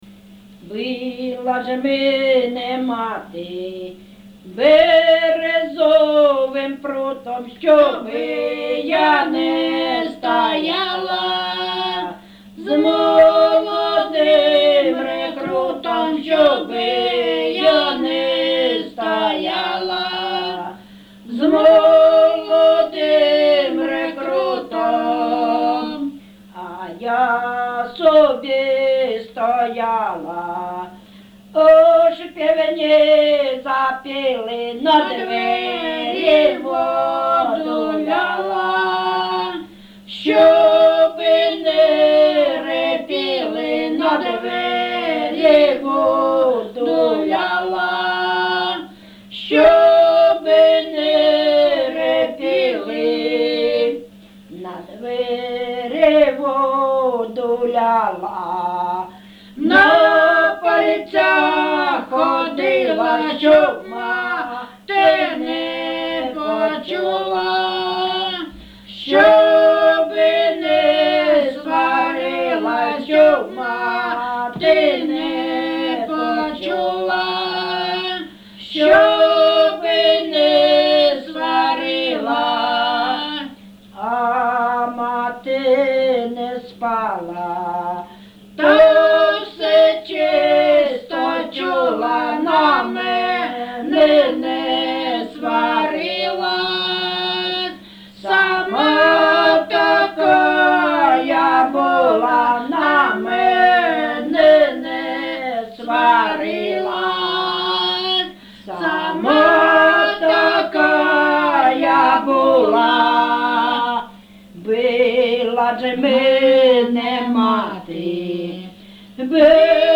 ЖанрПісні з особистого та родинного життя
Місце записум. Старобільськ, Старобільський район, Луганська обл., Україна, Слобожанщина